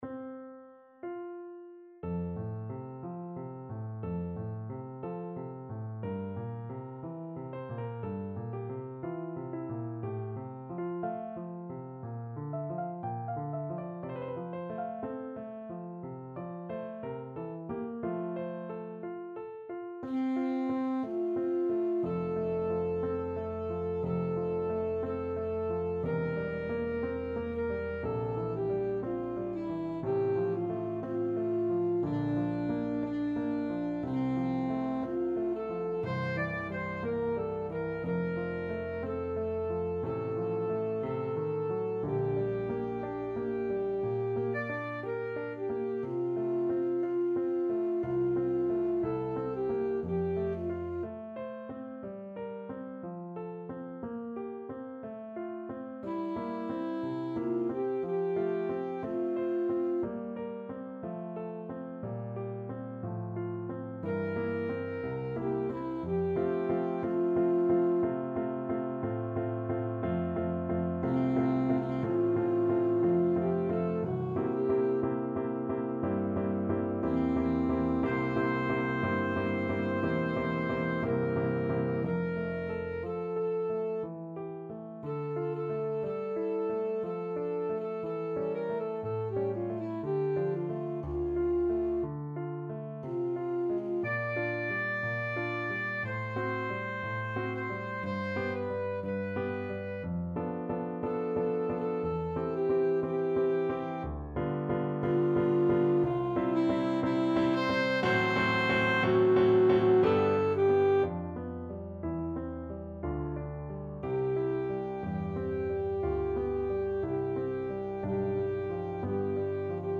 Free Sheet music for Alto Saxophone
Alto Saxophone
Bb4-E6
~ = 60 Larghetto
F major (Sounding Pitch) D major (Alto Saxophone in Eb) (View more F major Music for Saxophone )
4/4 (View more 4/4 Music)
Classical (View more Classical Saxophone Music)